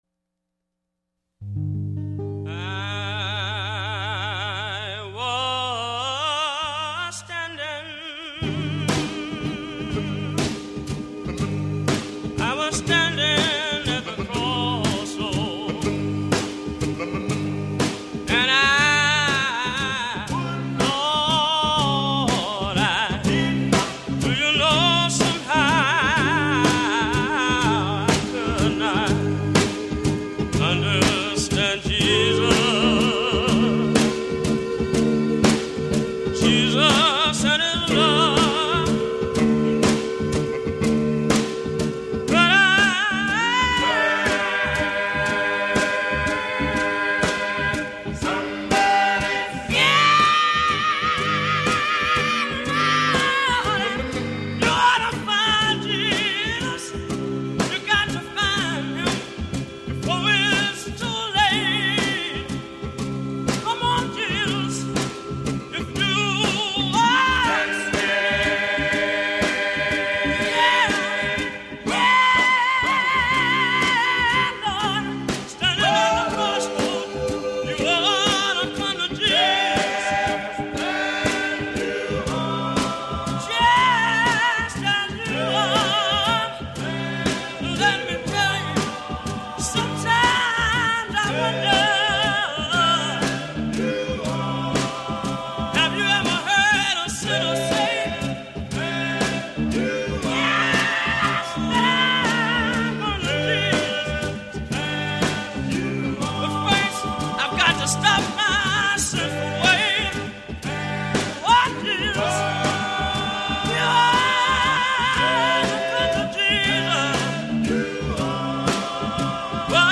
plays gospel musik